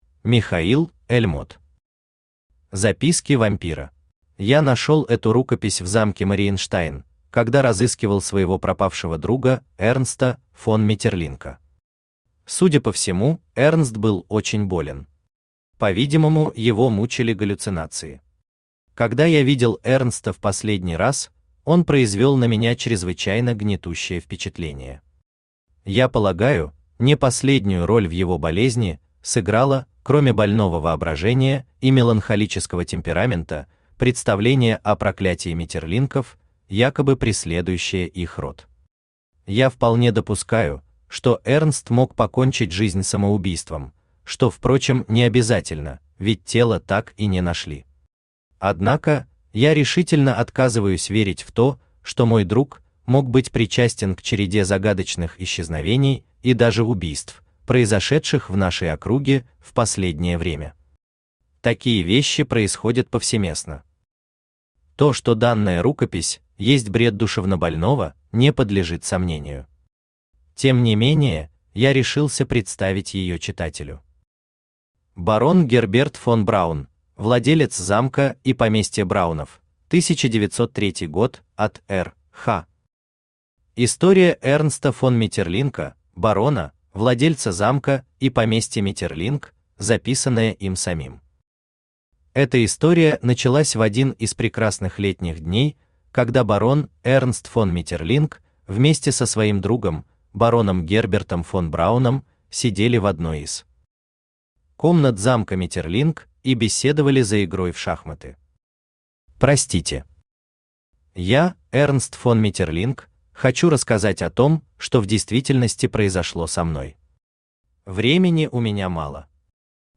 Aудиокнига Записки вампира Автор Михаил Эльмот Читает аудиокнигу Авточтец ЛитРес.